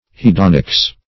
Search Result for " hedonics" : The Collaborative International Dictionary of English v.0.48: Hedonics \He*don"ics\, n. (Philos.) That branch of moral philosophy which treats of the relation of duty to pleasure; the science of practical, positive enjoyment or pleasure.